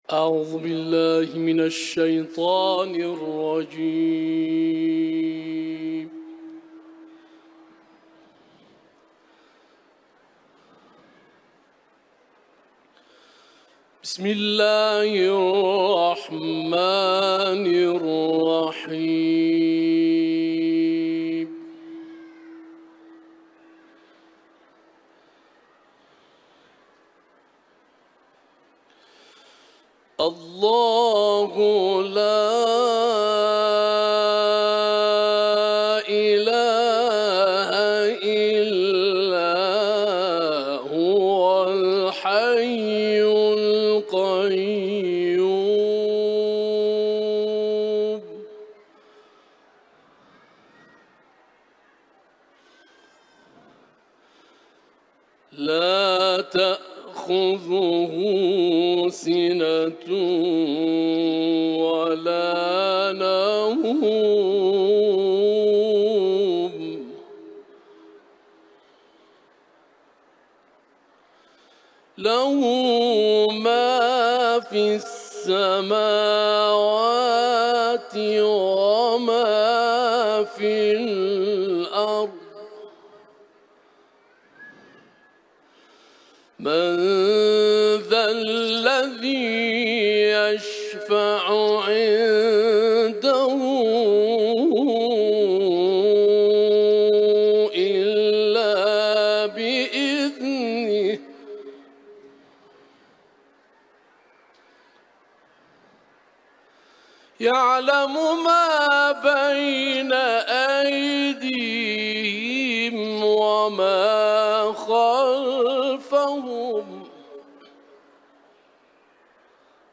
Etiketler: İranlı kâri ، Ayetel Kürsü ، Kuran tivaleti